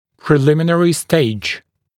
[prɪ’lɪmɪnərɪ steɪʤ][при’лиминэри стэйдж]предварительный этап